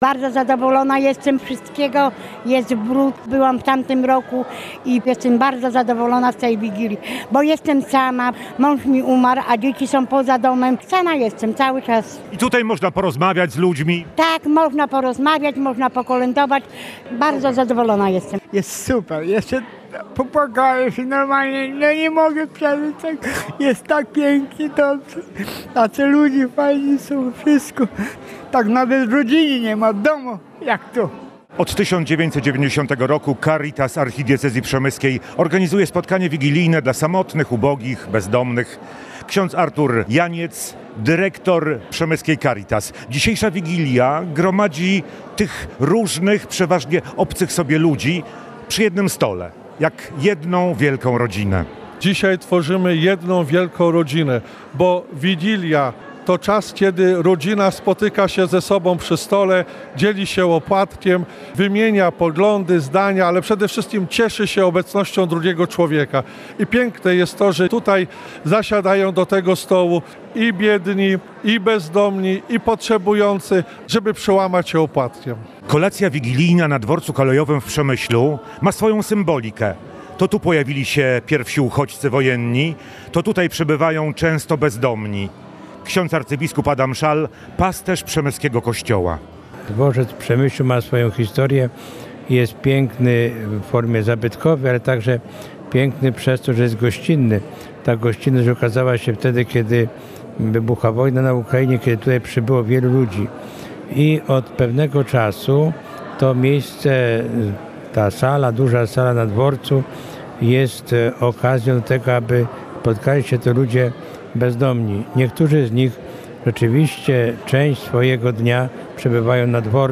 Caritas Archidiecezji Przemyskiej po raz kolejny zorganizowała kolację wigilijną dla ludzi ubogich, bezdomnych i samotnych. Spotkanie odbyło się 22 grudnia w restauracji „Perła Przemyśla” na dworcu PKP.
Nie mogło zabraknąć wspólnie śpiewanych kolęd.